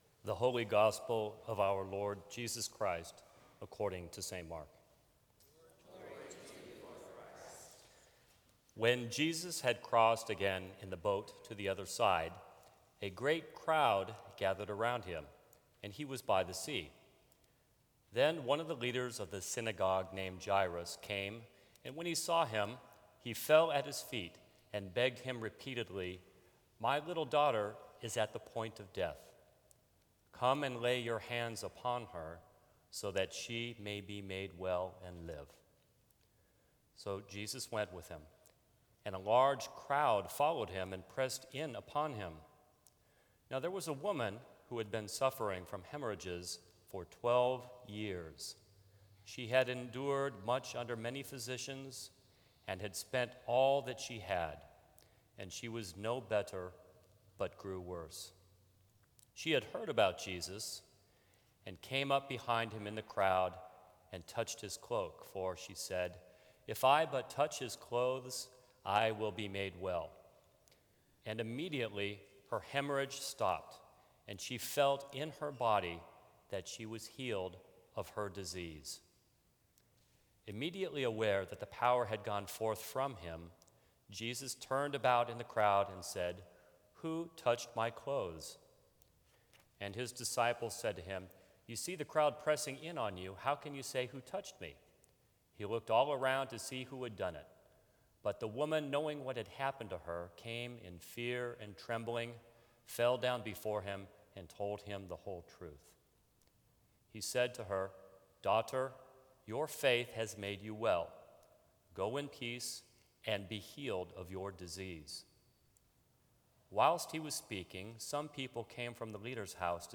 Sermons from St. Cross Episcopal Church The Miracle Behind the Miracles Sep 25 2015 | 00:10:04 1x 00:00 / 00:10:04 Subscribe Share Apple Podcasts Spotify Overcast RSS Feed Share Link Embed